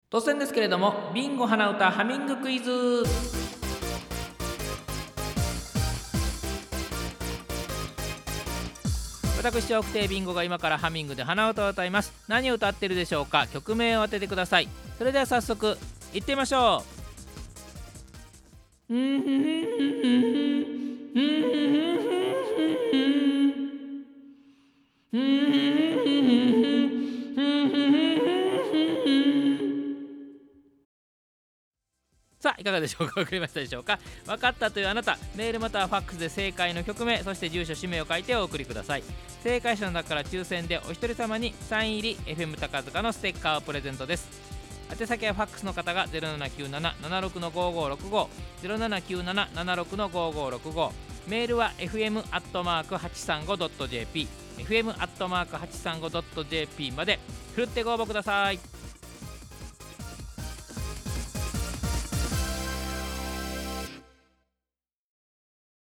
はなうたクイズ ヒントが聞けます！